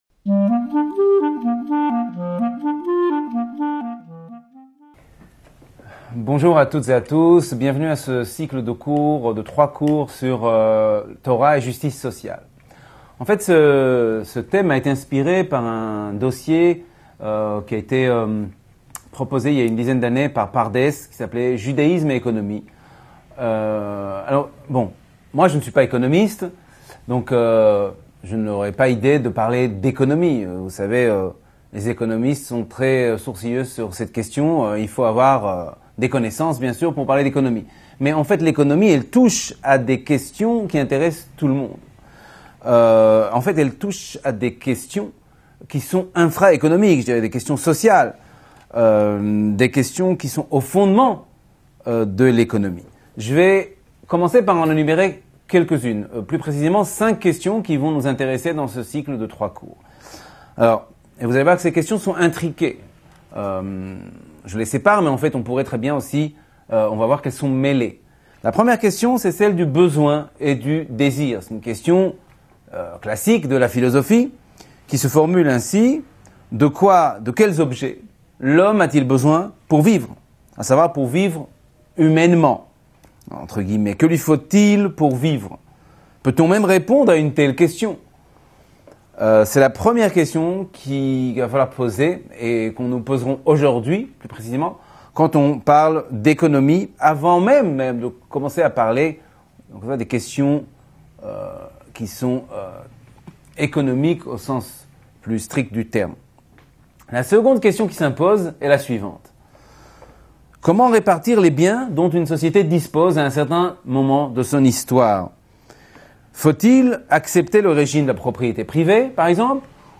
Torah et justice sociale - Cours N°1/3